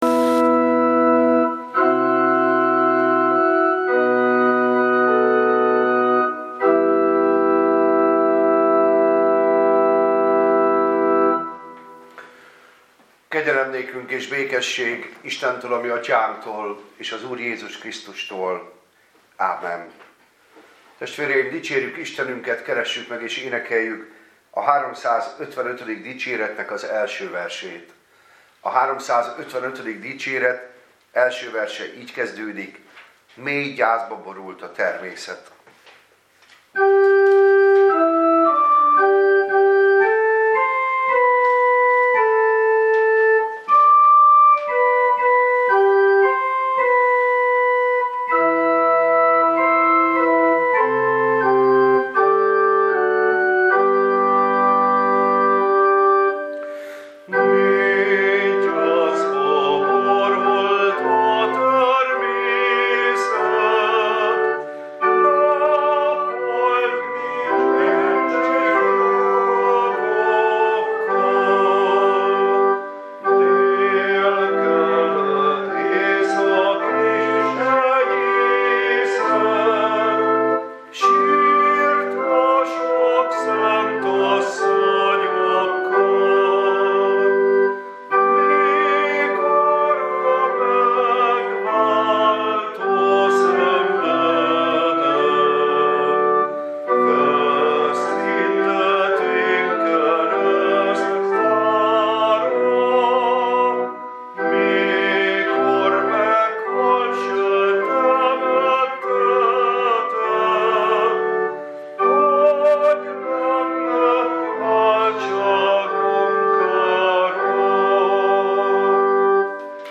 Passage: Mt 27,43-54, Mt 27,62-64 Service Type: Igehirdetés